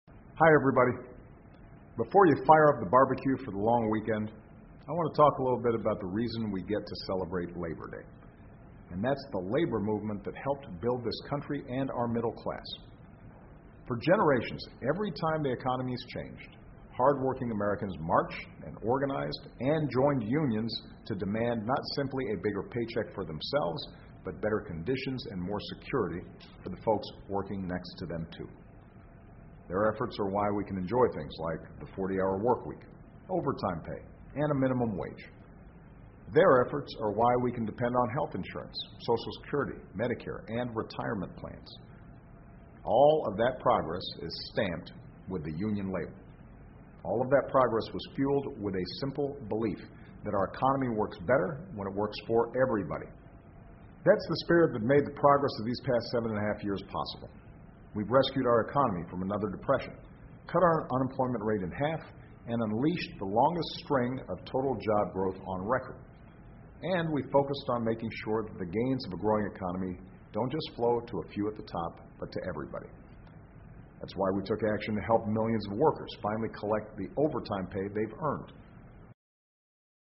奥巴马每周电视讲话：总统阐释劳工节传承的财富（01） 听力文件下载—在线英语听力室